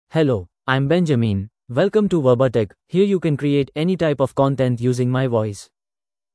Benjamin — Male English (India) AI Voice | TTS, Voice Cloning & Video | Verbatik AI
MaleEnglish (India)
Benjamin is a male AI voice for English (India).
Voice sample
Listen to Benjamin's male English voice.
Benjamin delivers clear pronunciation with authentic India English intonation, making your content sound professionally produced.